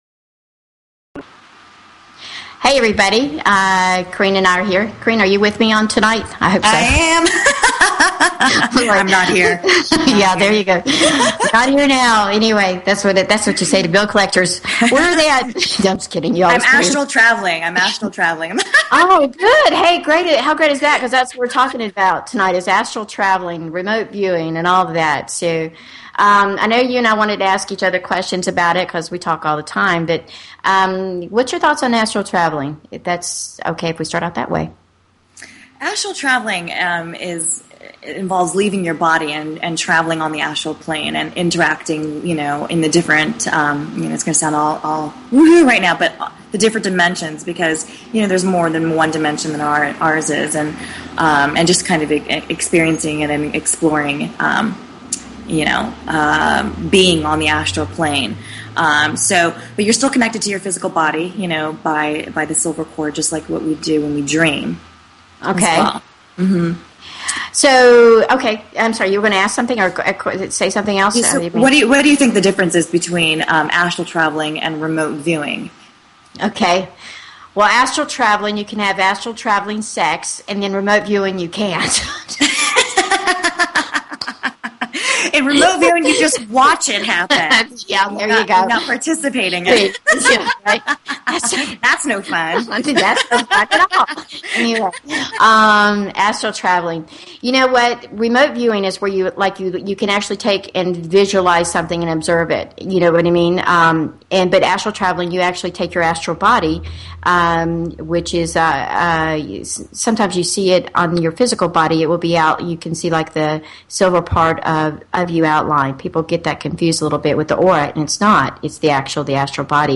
Talk Show Episode, Audio Podcast, Angel_Coaches and Courtesy of BBS Radio on , show guests , about , categorized as